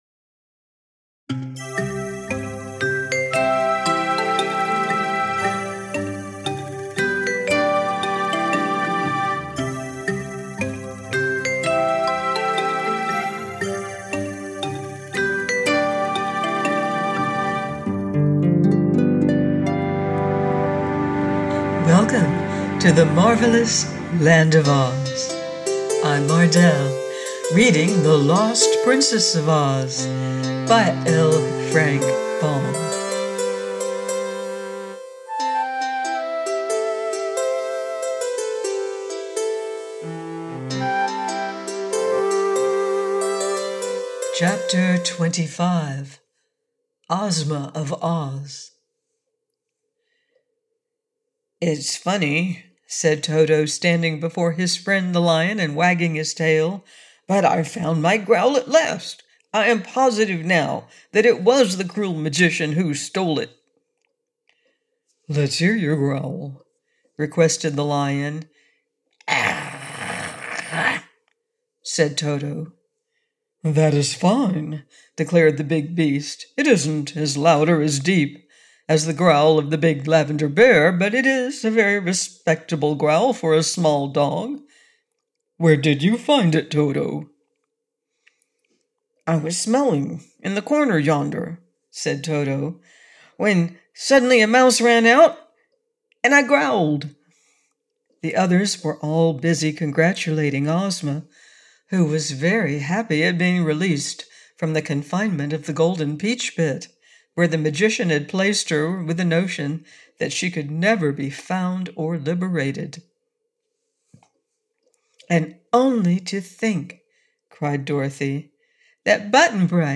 The Lost Princess of Oz by Frank L. Baum - AUDIOBOOK